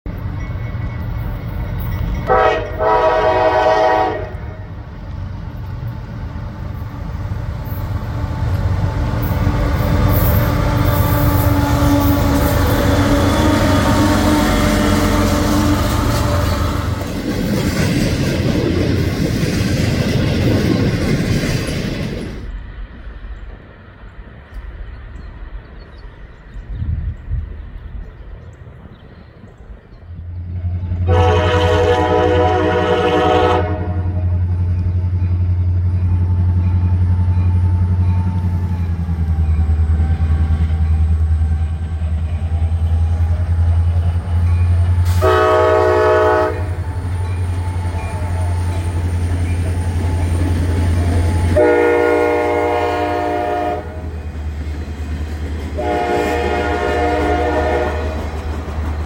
EMD Powered Weed Sprayer and a YN2 leads X555 through Pimento, Indiana
TYPE: Work Train (Weed Sprayer) LOCOMOTIVES SOLO: CSXT 8845 (EMD SD40-2)